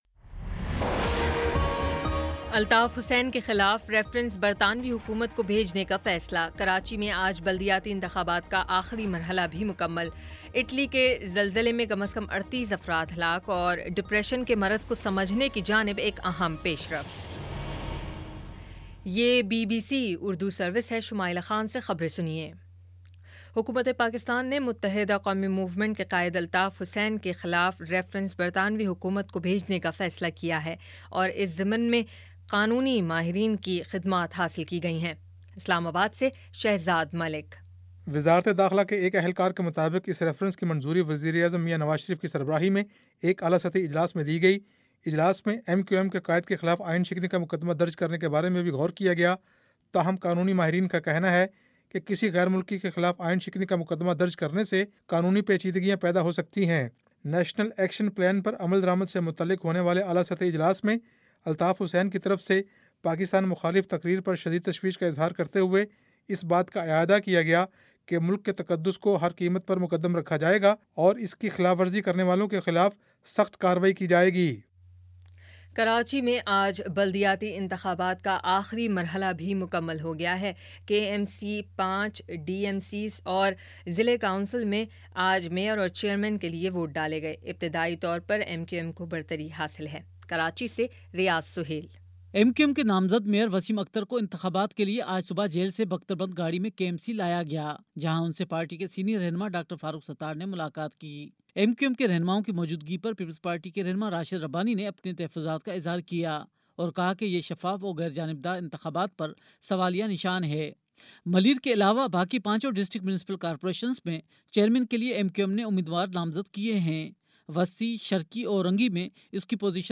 اگست 24 : شام پانچ بجے کا نیوز بُلیٹن